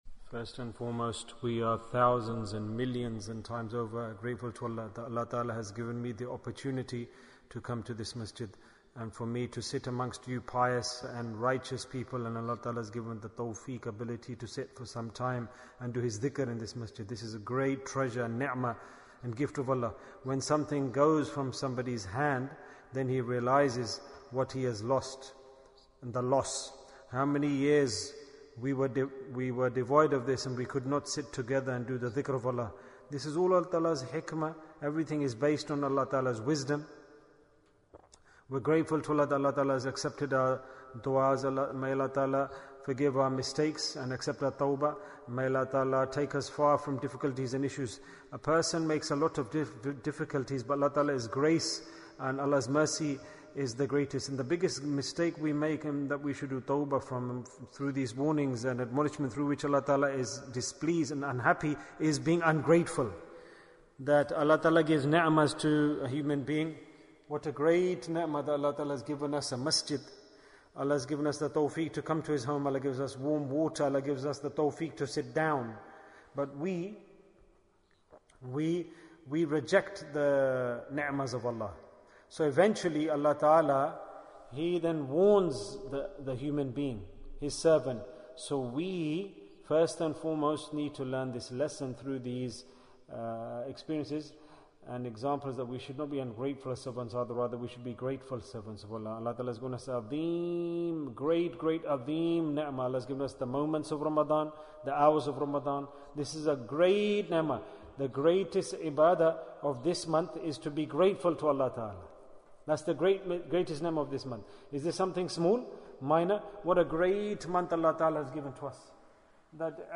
Ramadhan Bayan Bayan, 11 minutes19th April, 2021